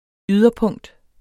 Udtale [ ˈyðʌ- ]